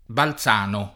balZ#no] agg.